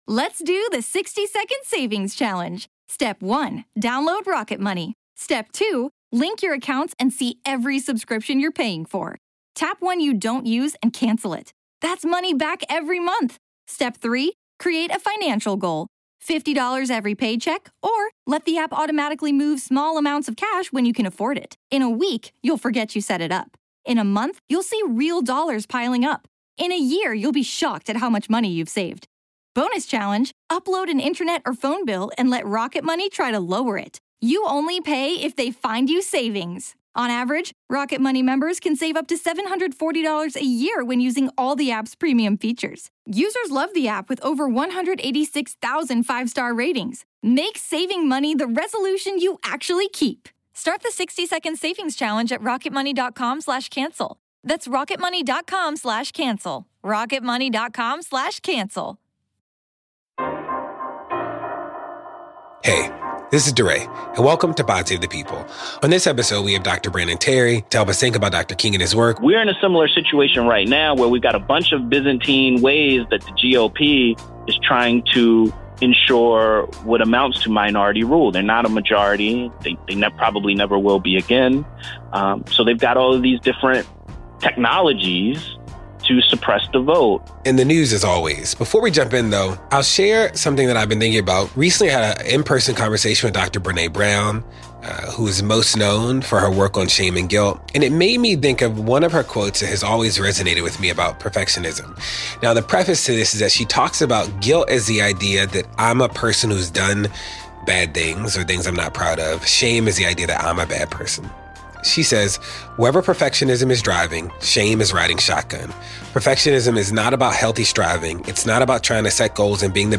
Clint and Brittany join DeRay to talk the news during our first Black History Month podcast.